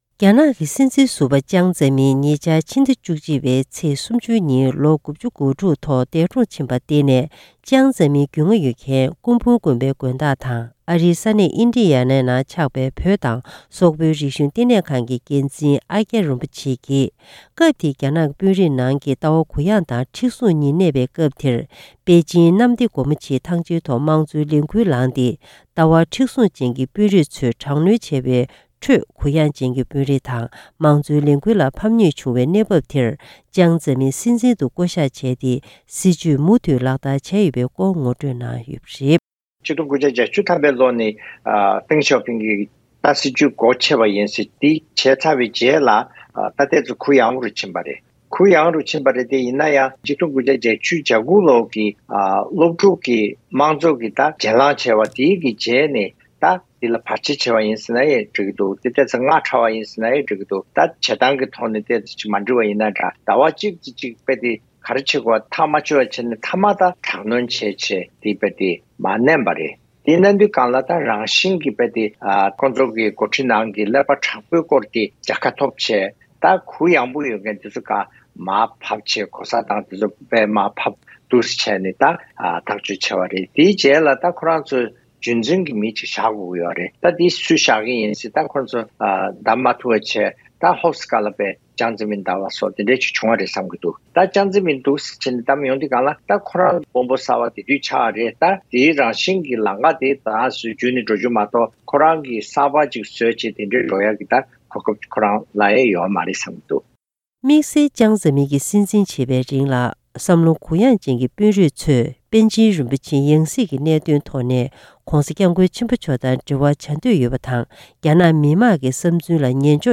སྒྲ་ལྡན་གསར་འགྱུར།
ཐེངས་འདིའི་གསར་འགྱུར་དཔྱད་གཏམ་གྱི་ལེ་ཚན་ནང་།